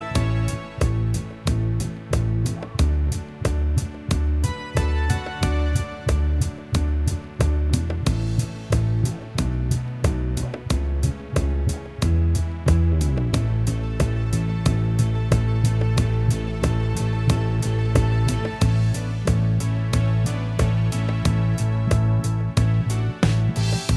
Minus Guitars Pop (1970s) 3:50 Buy £1.50